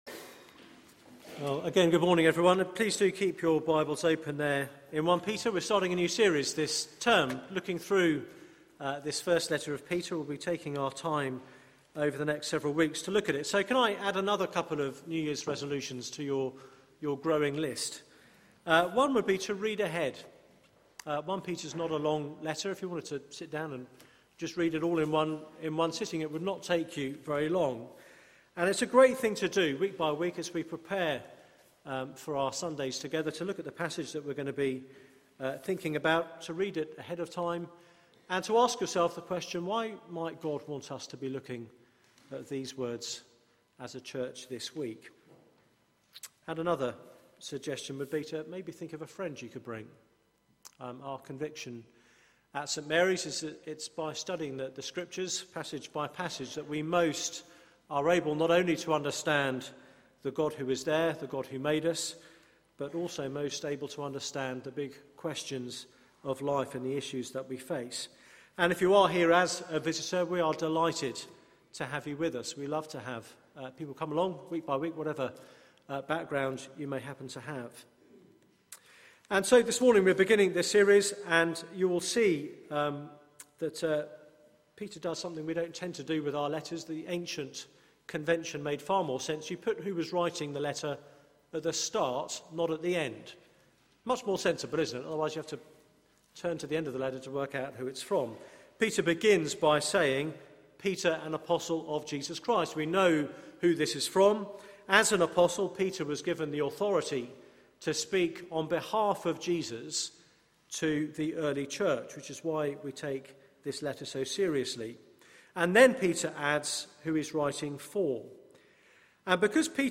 Media for 9:15am Service on Sun 05th Jan 2014 09:15 Speaker
THE CHURCH UNDER PRESSURE Theme: Know who you are Sermon